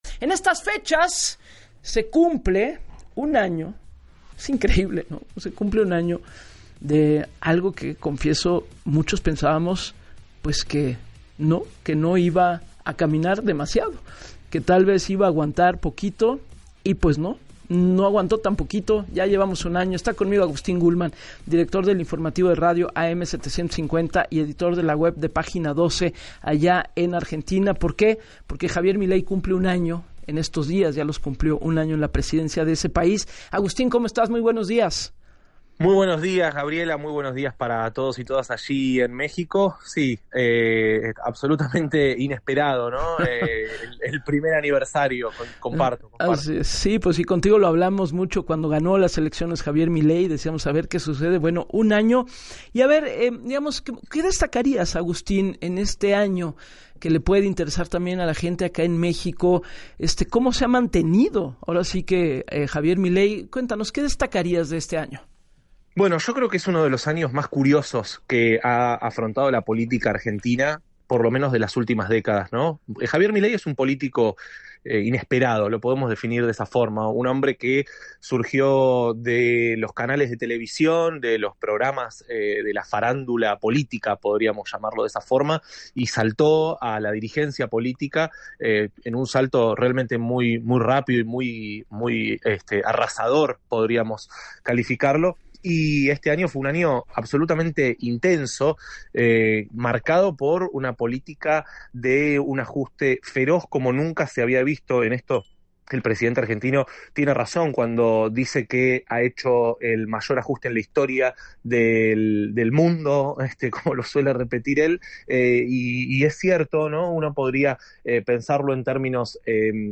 En el espacio de “Así las Cosas”, con Gabriela Warkentin, el periodista hizo un recuento de este primer año de mandato del presidente argentino, que pese a todo tiene una buena percepción, con el 50% de acuerdo con encuestas.